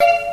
neptunespiano03.wav